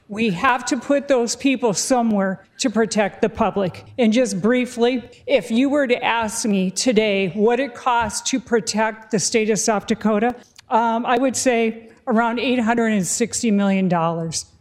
Republican Rep. Mary Fitzgerald from Spearfish cited public safety concerns regarding recent violent crimes.